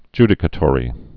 (jdĭ-kə-tôrē)